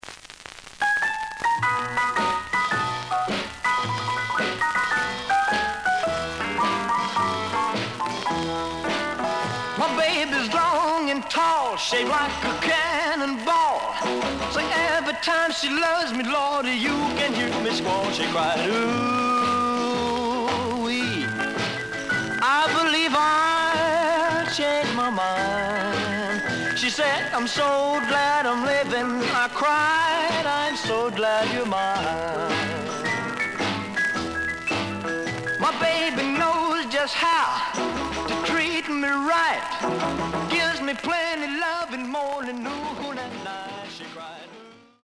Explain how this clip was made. The listen sample is recorded from the actual item. Slight edge warp.